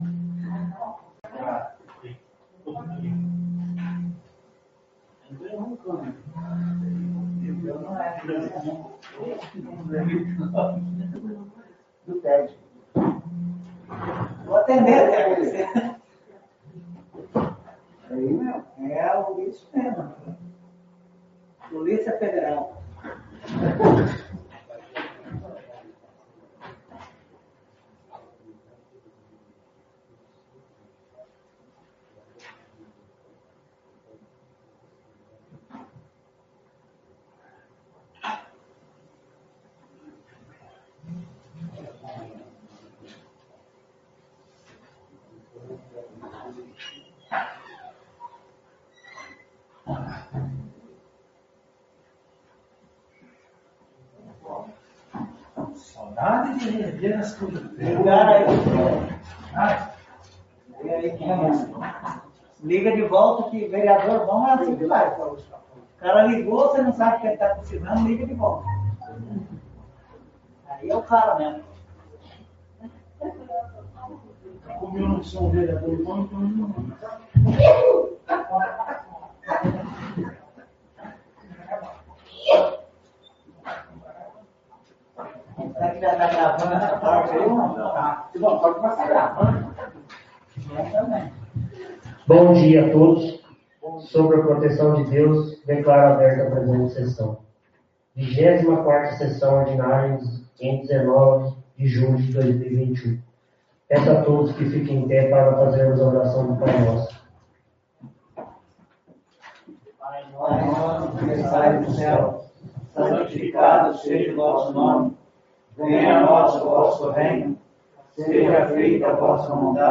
24ª Sessão Ordinária